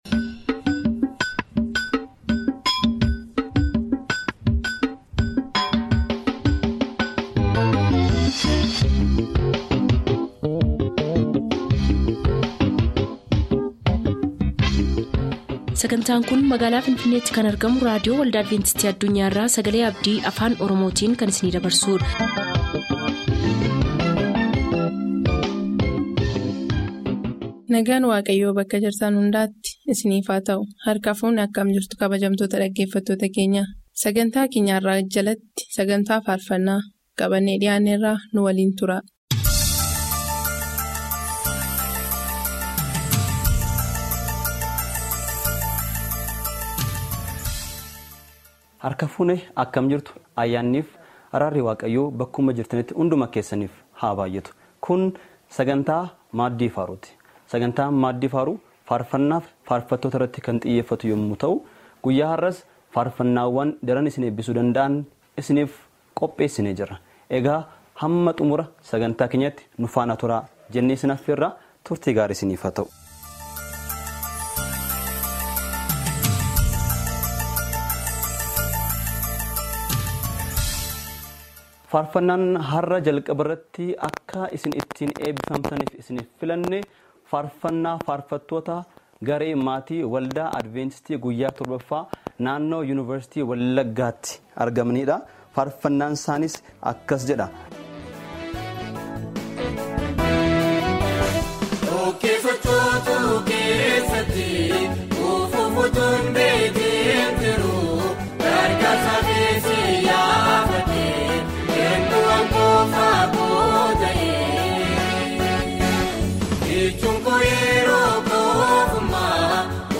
SAGANTAA FAARFANNAA SAGALEE ABDII AFAAN OROMOO. SONG PROGRAME FROM ADVENTIST WORLD RADIO OROMO